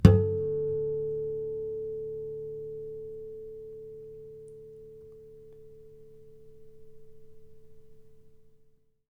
harmonic-09.wav